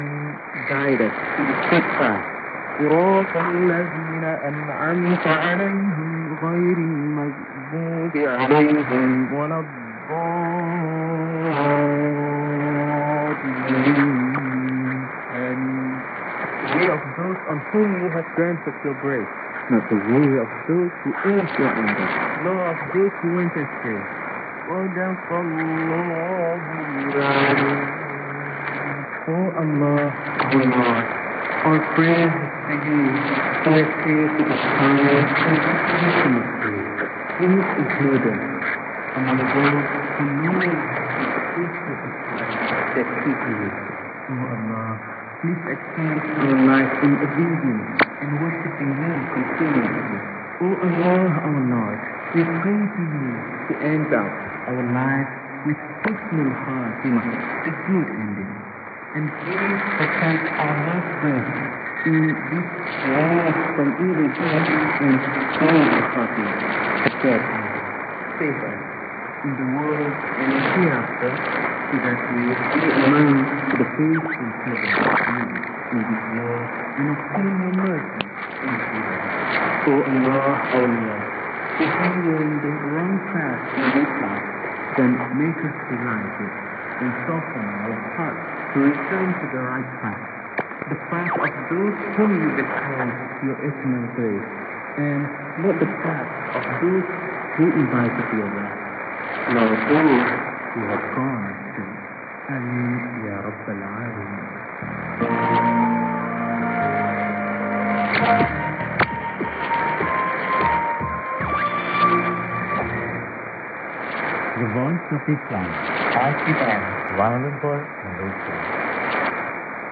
・このＨＰに載ってい音声(ＩＳとＩＤ等)は、当家(POST No. 488-xxxx)愛知県尾張旭市で受信した物です。
ID: identification announcement